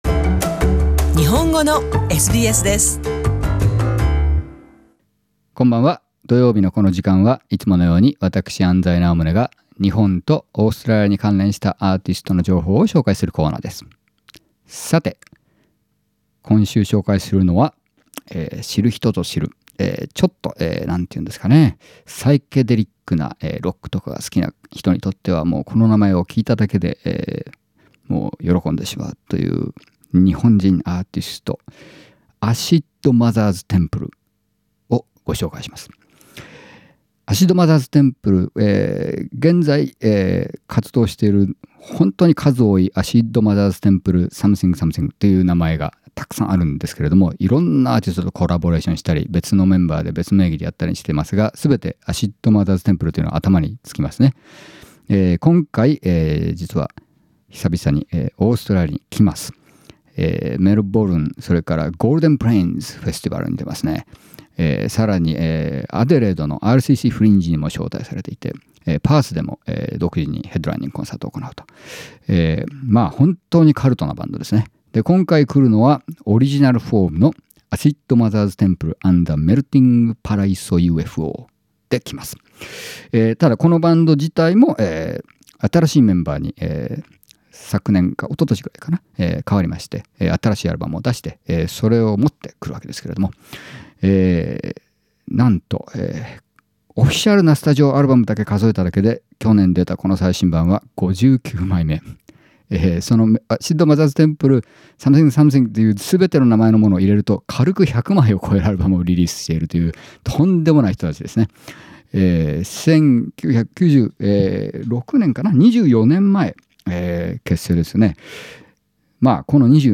Japanese psychedelic rock band.